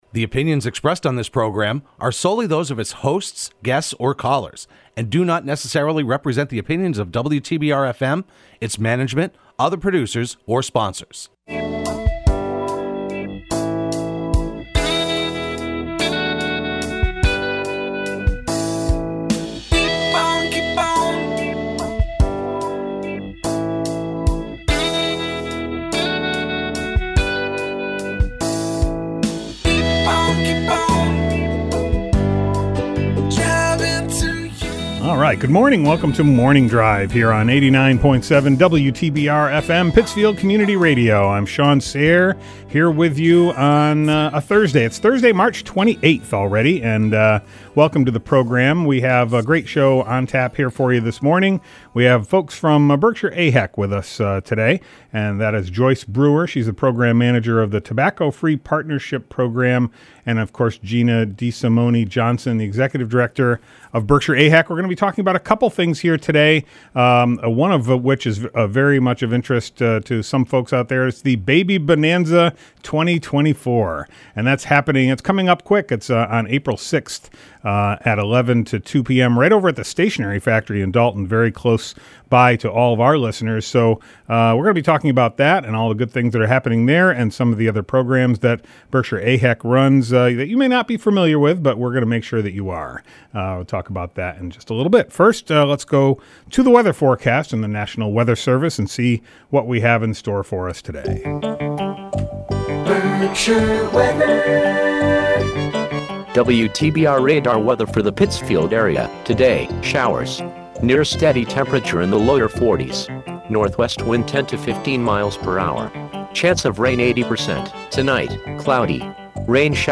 live edition